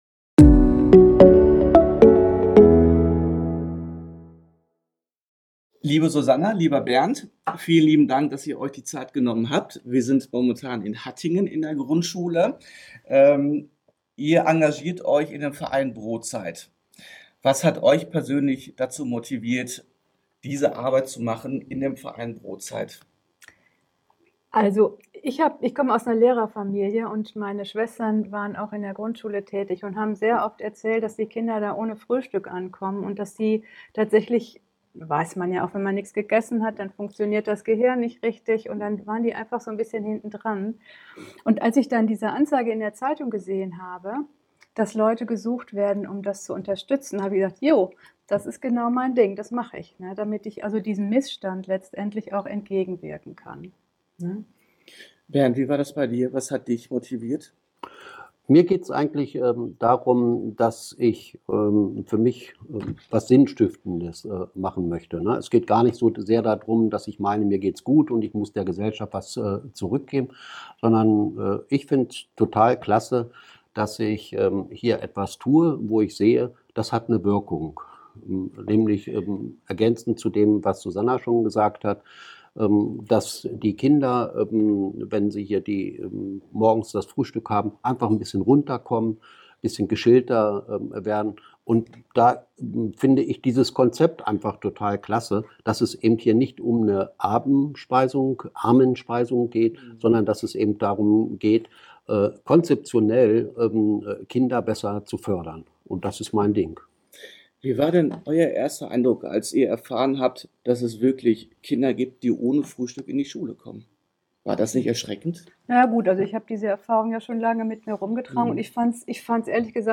Podcast: Interview mit brotZeit e. V. - EN-Aktuell